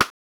08 rim hit.wav